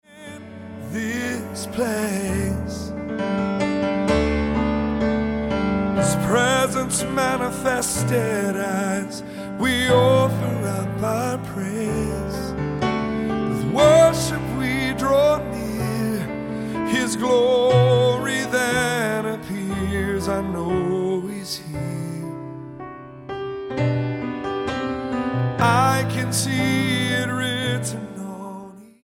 STYLE: Gospel
grand piano, the mood is downbeat throughout